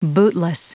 Prononciation du mot bootless en anglais (fichier audio)
Prononciation du mot : bootless